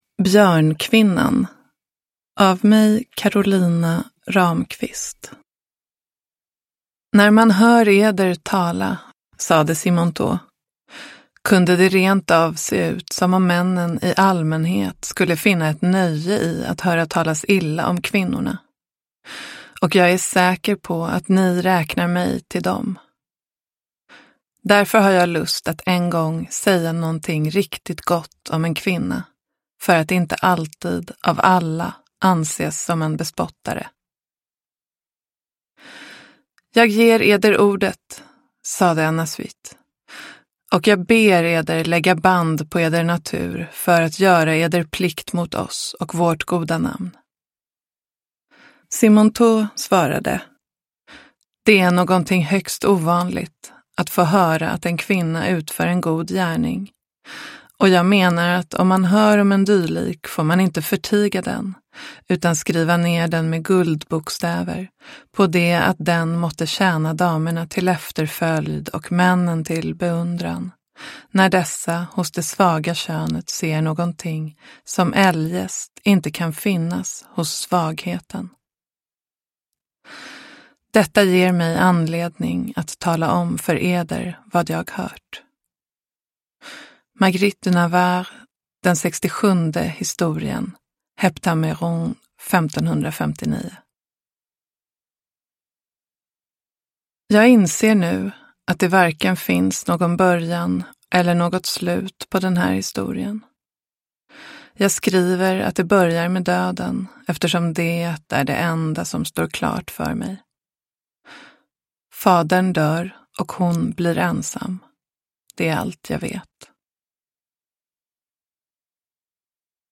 Uppläsare: Karolina Ramqvist